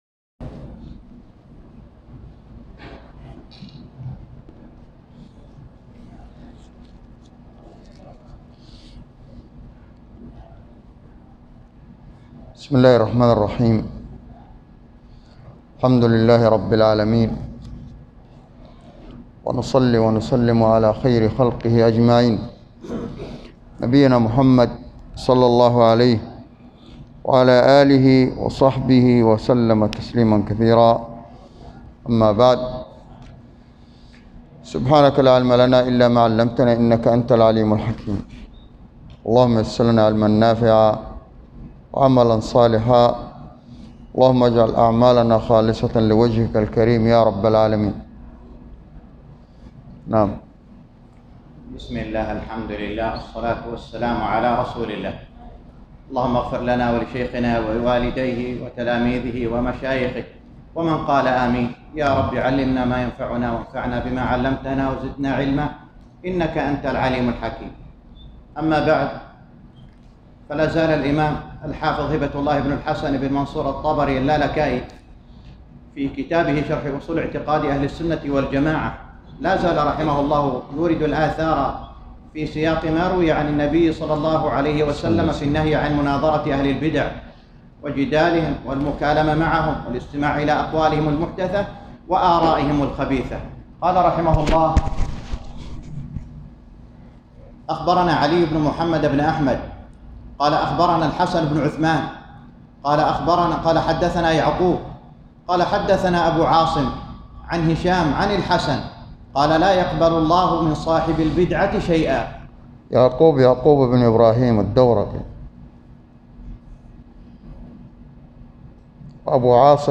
الدرس الخامس والأربعون - شرح أصول اعتقاد اهل السنة والجماعة الامام الحافظ اللالكائي _ 45